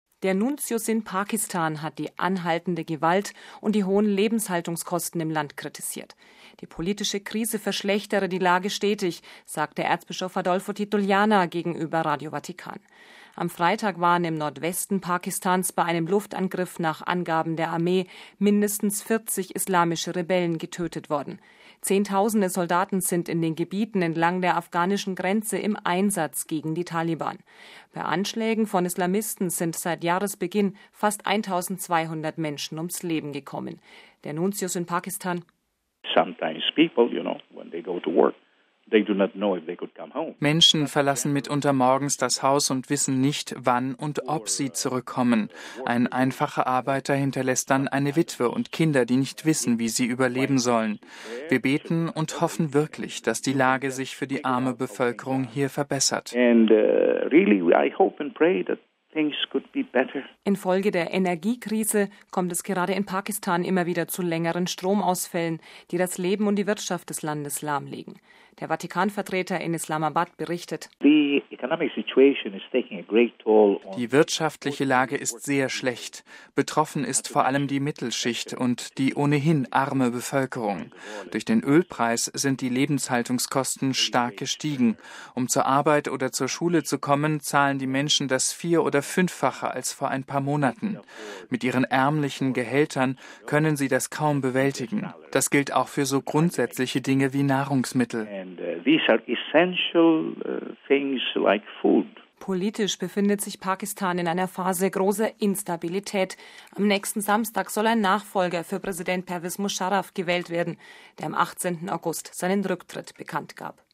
Die politische Krise verschlechtere die Lage stetig, sagte Erzbischof Adolfo Tito Yllana gegenüber Radio Vatikan.
Der Nuntius in Pakistan: